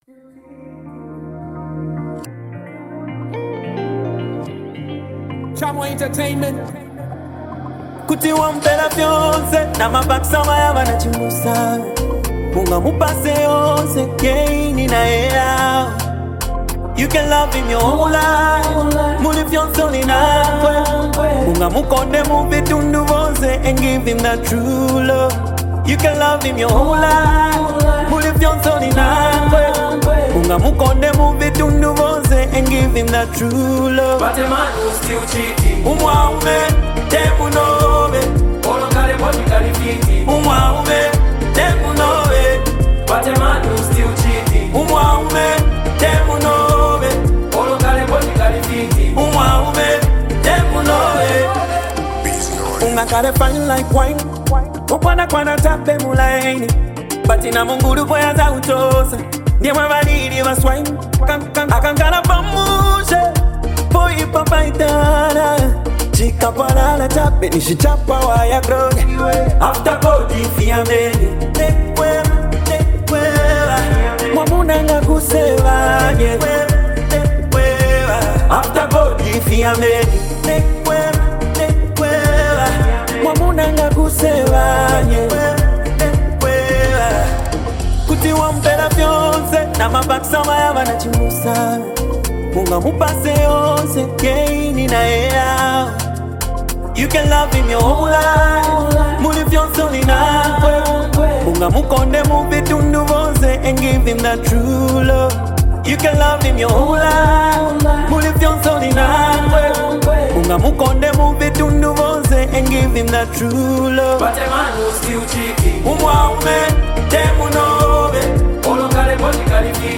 a bold and reflective piece